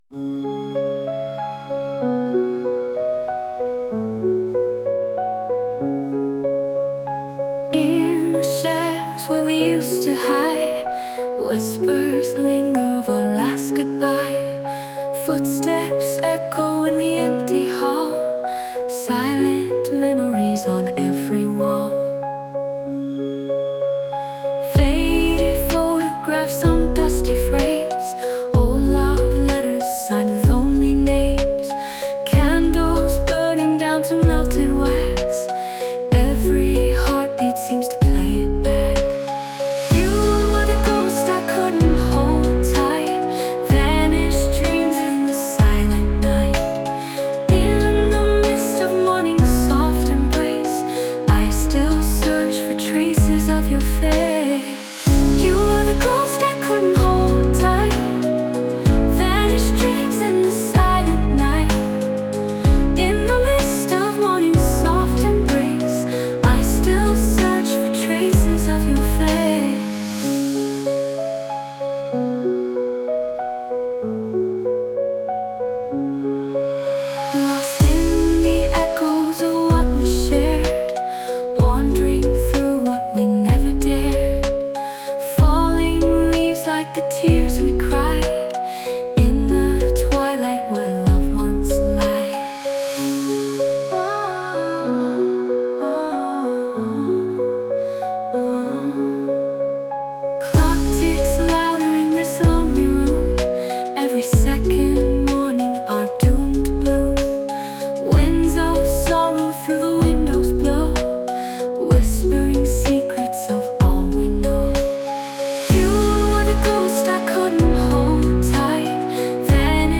Musique générée par IA.